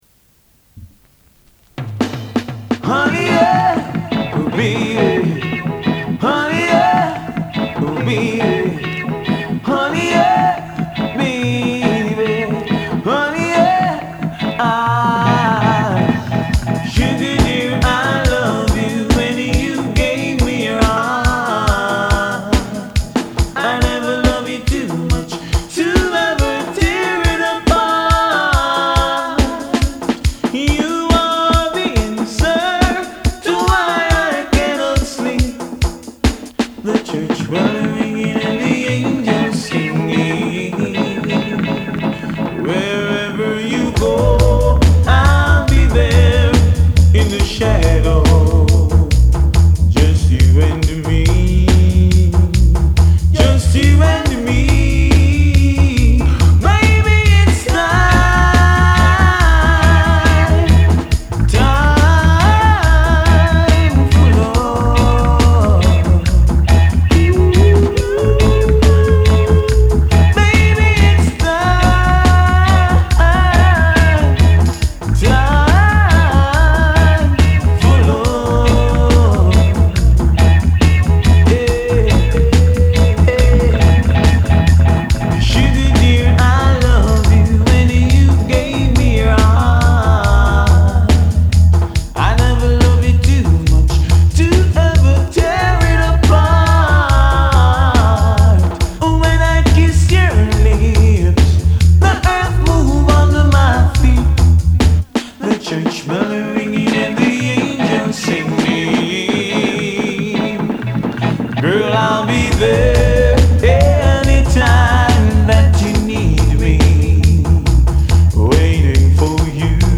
This tape
while side B is a collection of hardcore ninties tune.